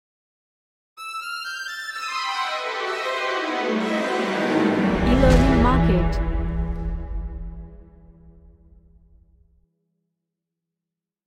Futuristic Orchestral Running
Dark / Somber